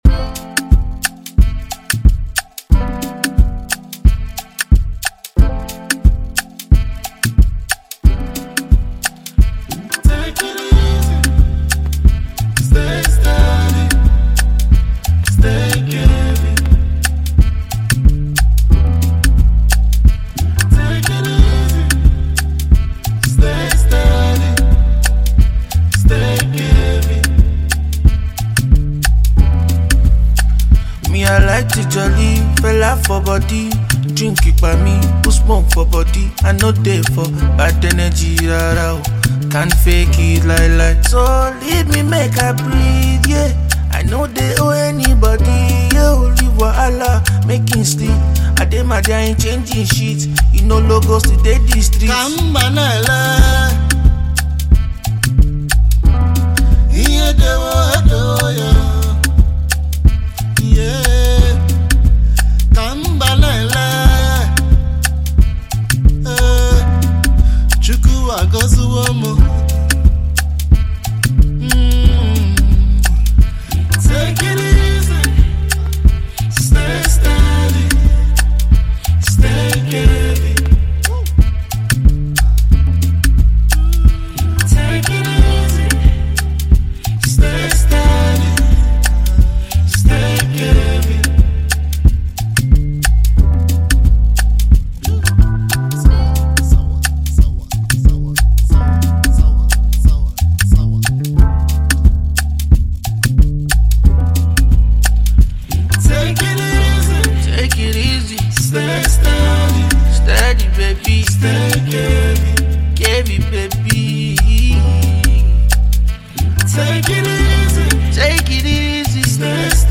British-Nigerian singer-songwriter
Nigerian Highlife duos